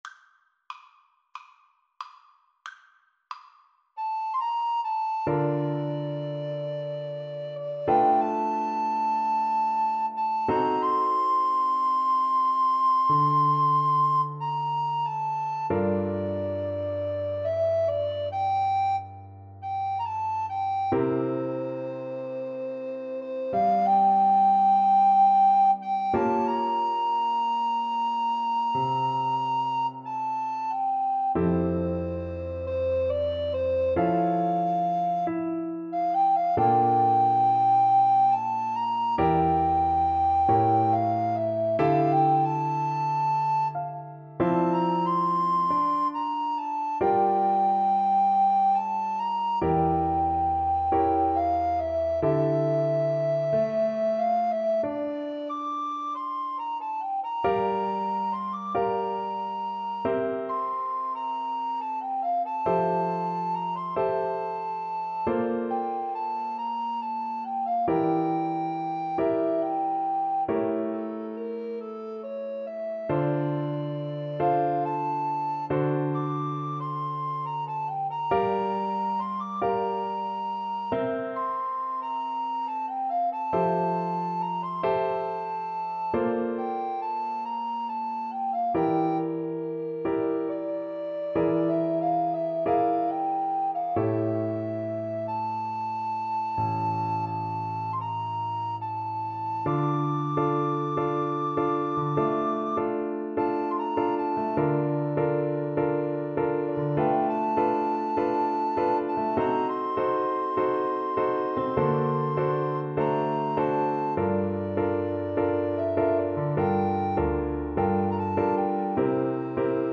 4/4 (View more 4/4 Music)
Andante =c.92
Jazz (View more Jazz Alto Recorder Duet Music)